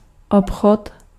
Ääntäminen
US : IPA : [ˈkɒ.mɜːs] UK US : IPA : /ˈkɑ.mɝs/ UK : IPA : /ˈkɒ.mɜs/